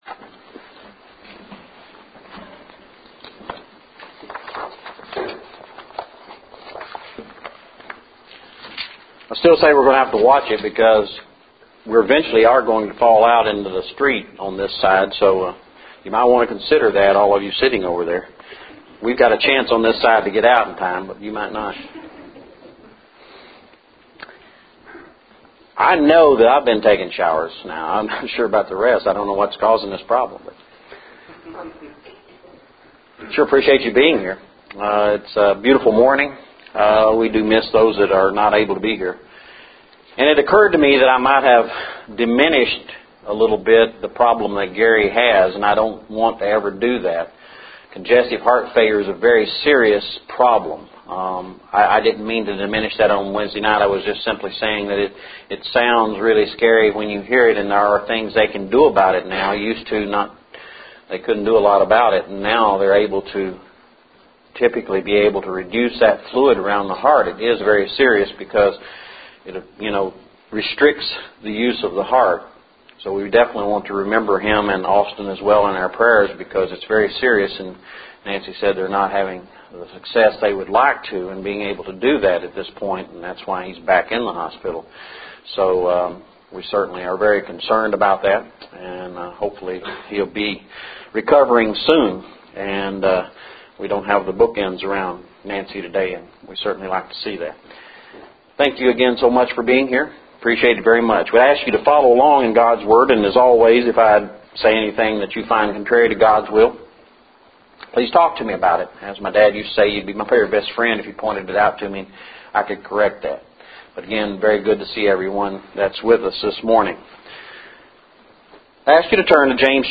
Recorded Lessons